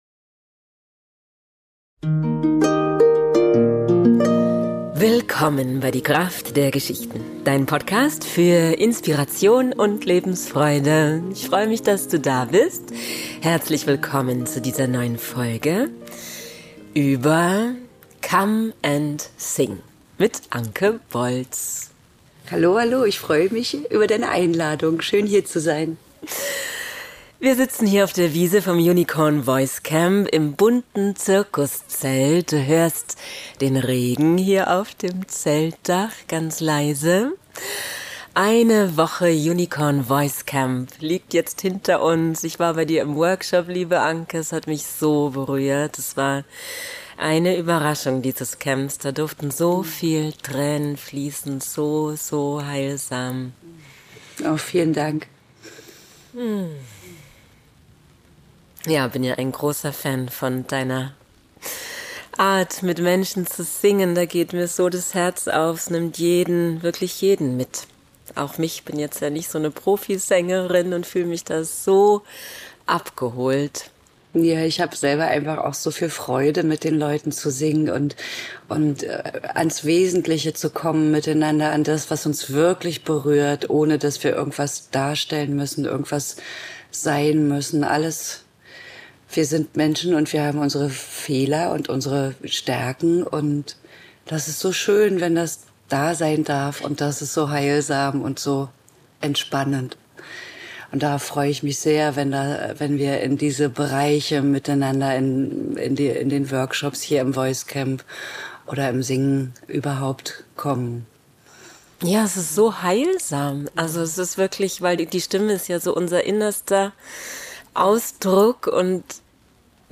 Heute nehmen wir Dich mit aufs Unicorn Voice Camp.
Viel Freude mit diesem neuen Interview.